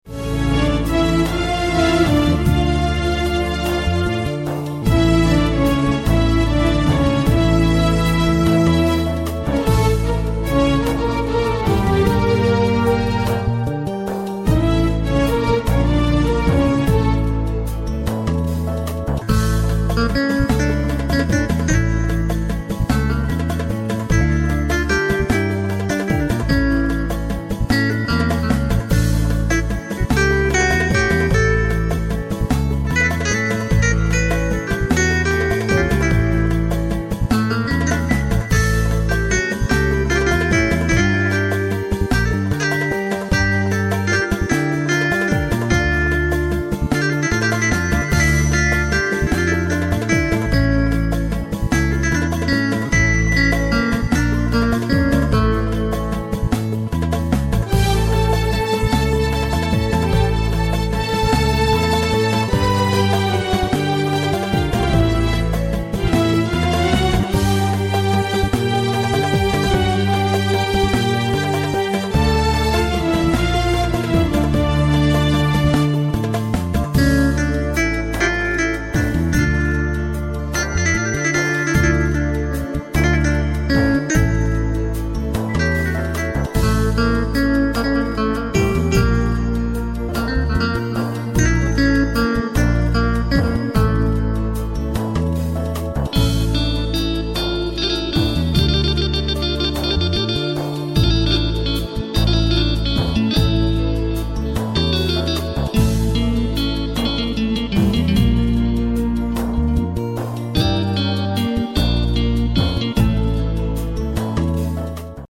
Şöyle ritimlerde canlı canlı dolaştım.